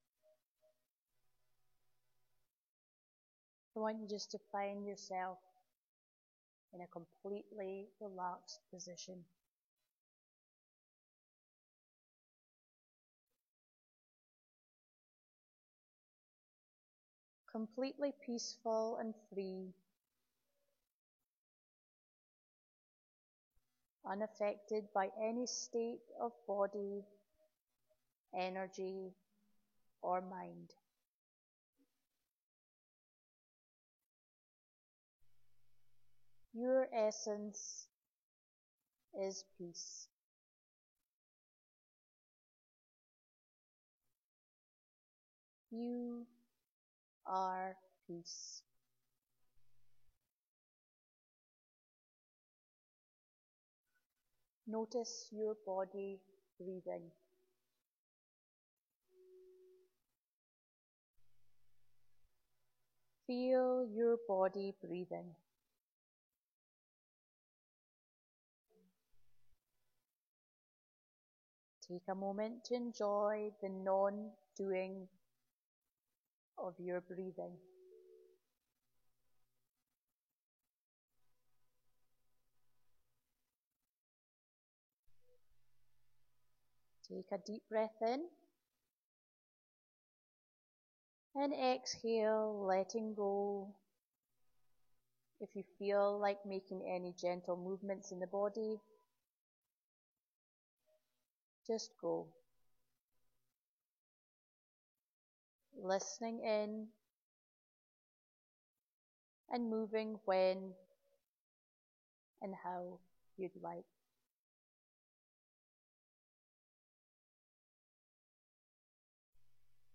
Moments of Stillness: A 6-Minute Meditation